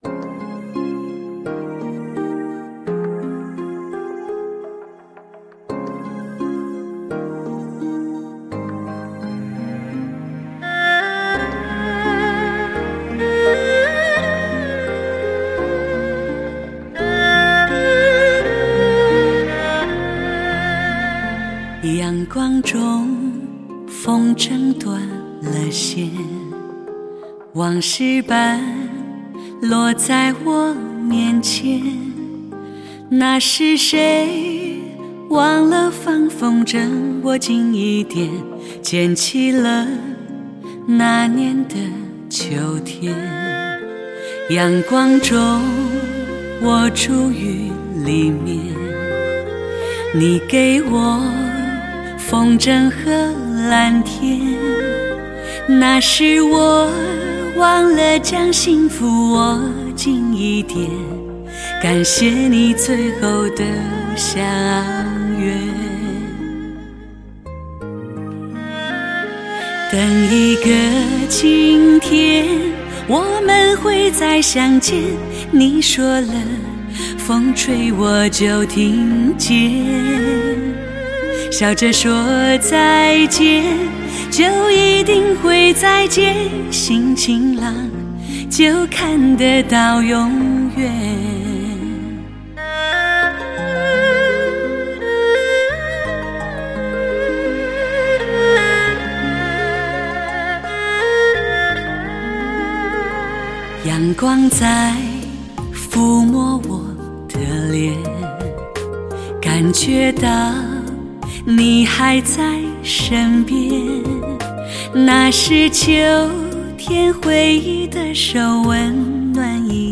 极致发烧的音响效果